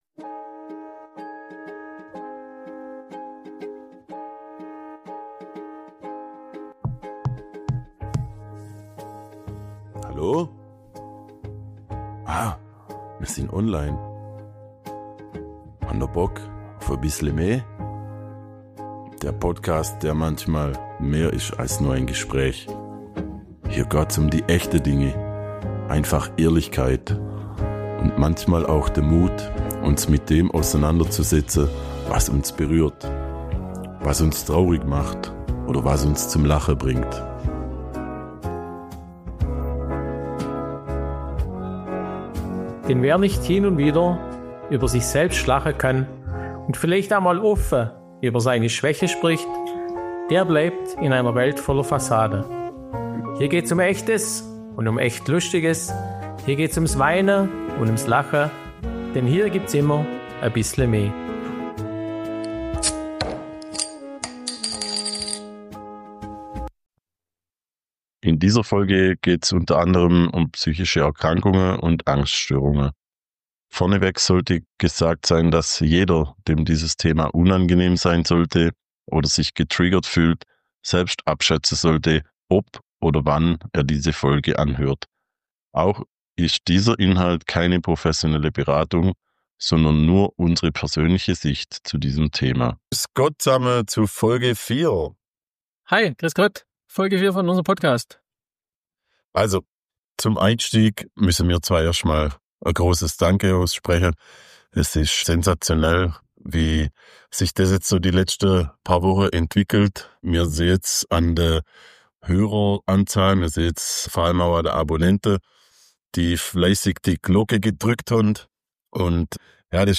#04 Ein Herzensthema: Über Ängste, Panik und offenes Schwätza ~ Bissle me – Schwoba-Podcast aus´m Schlofsack Podcast
In dieser Folge wird’s bei "bissle me" mal a bissle ruhiger – aber nicht weniger wichtig. Wir reden über etwas, das viele kennen, aber nur wenige offen ansprechen: psychische Erkrankungen.